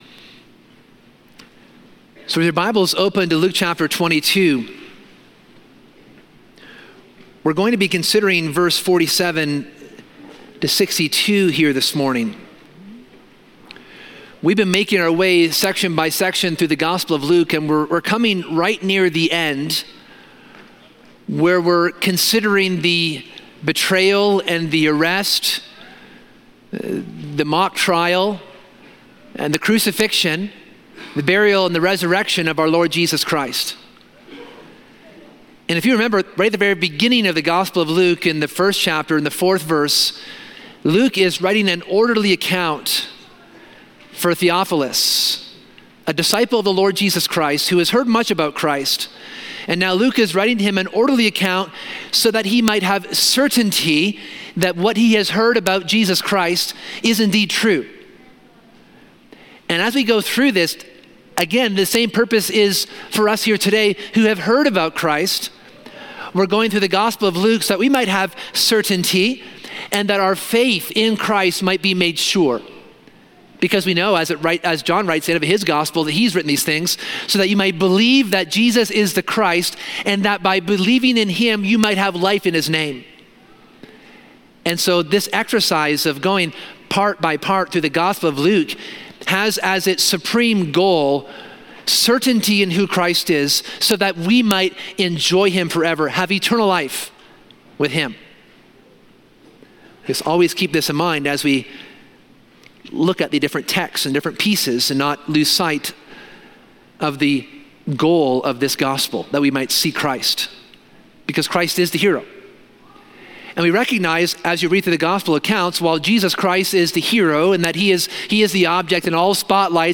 This sermon examines the betrayal of Judas, Peter’s denial, and the arrest of Christ. While the apostles fall away, there are lessons of humility, repentance, and hope for us today.